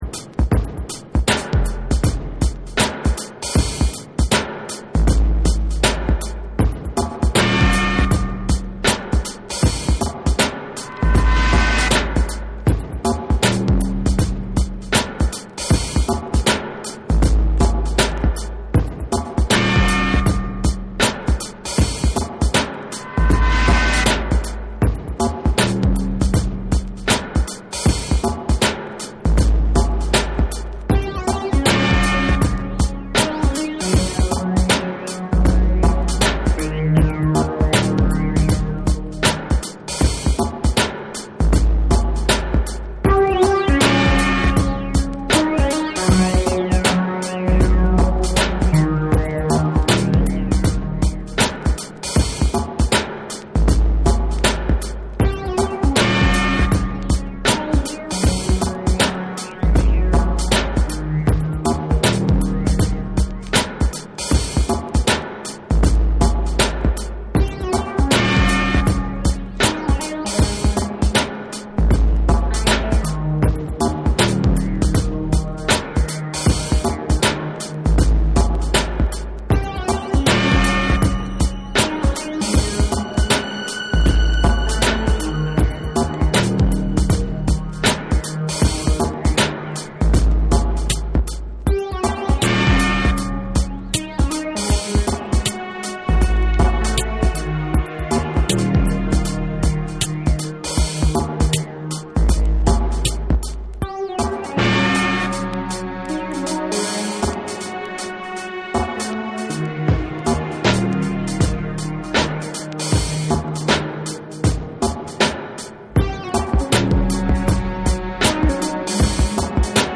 メタリックでエッジの効いた一音一音が強烈なビートがドープな世界観を作り上げる1(SAMPLE 1)。
TECHNO & HOUSE / BREAKBEATS